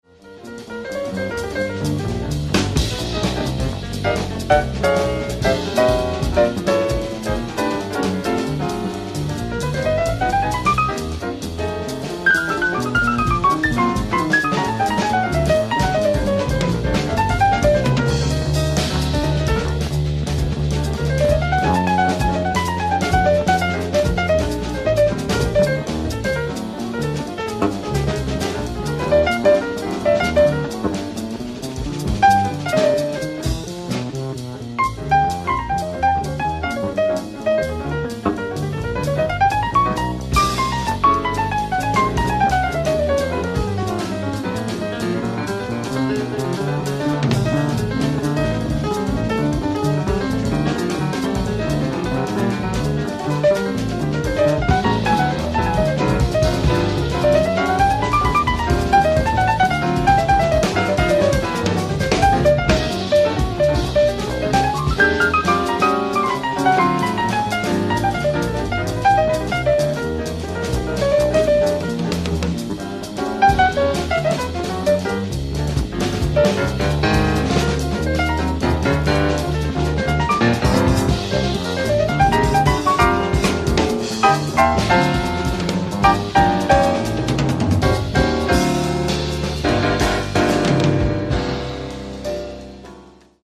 ライブ・アット・ファブリック、ハンブルグ、ドイツ 10/21/1987
※試聴用に実際より音質を落としています。